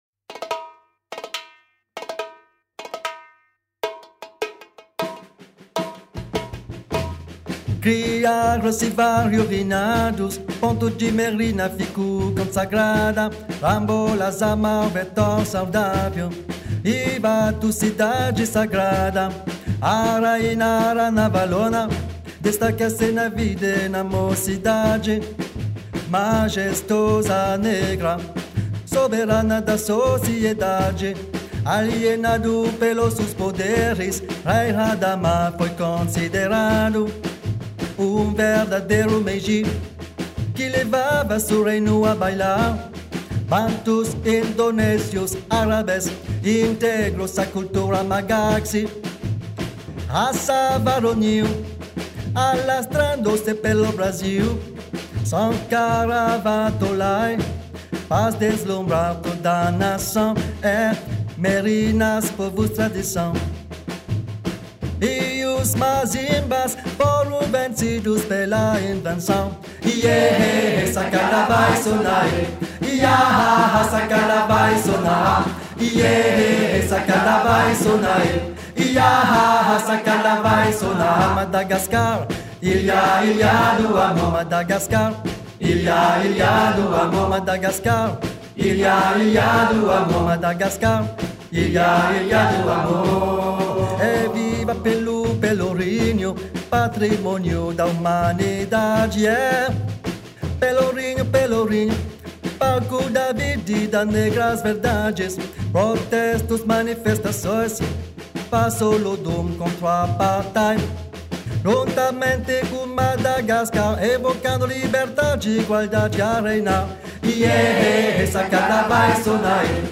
La batucada qui vous apporte le soleil!